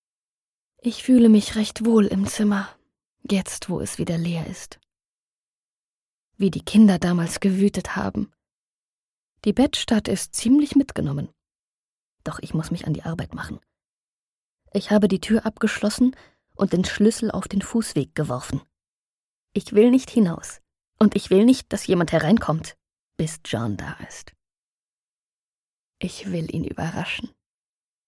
professionelle Sprecherin mit den Arbeitssprachen Deutsch und Englisch (UK, RP/Standard).
Hörbuch „Die gelbe Tapete“